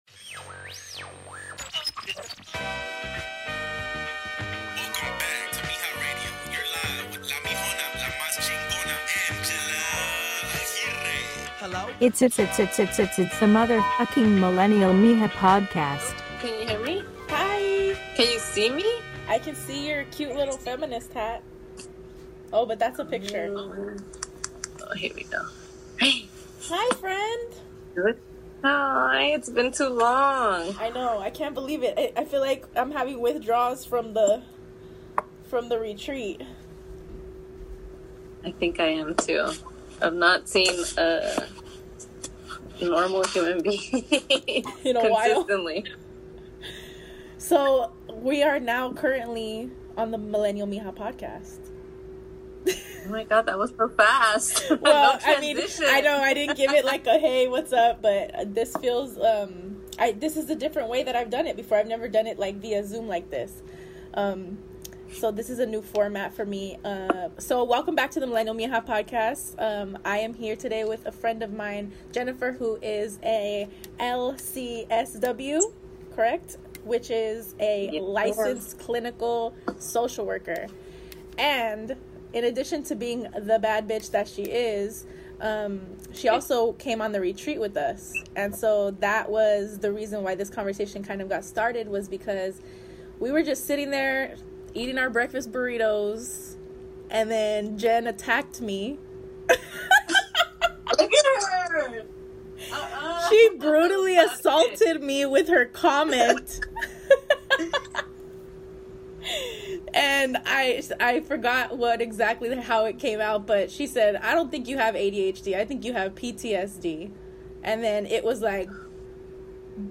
a conversation about the Season of Self Retreat, PTSD, and the importance of community in a healing journey.